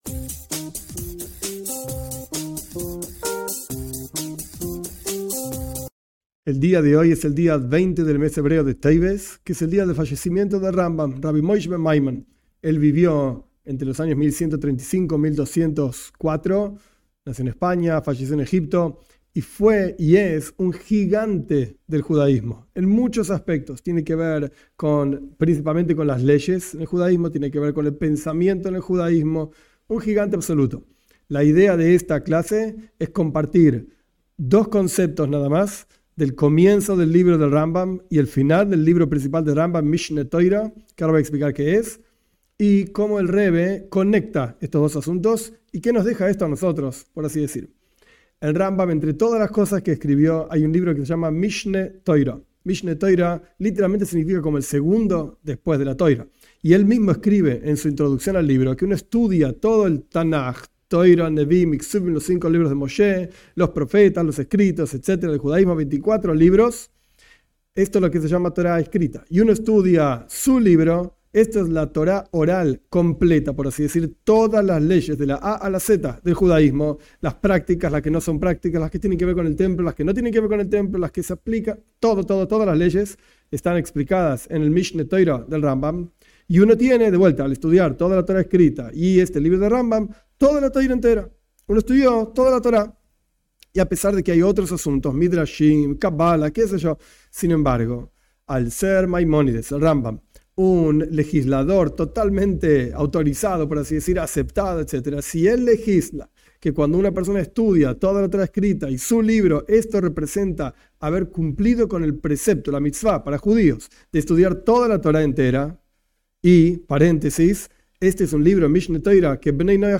En esta clase se conecta el comienzo del libro principal del Rambam, el Mishné Torá con el final, explicando el concepto del conocimiento de Di-s.